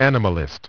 Транскрипция и произношение слова "animalist" в британском и американском вариантах.